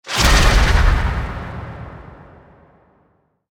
Sfx_creature_iceworm_closeshake_01.ogg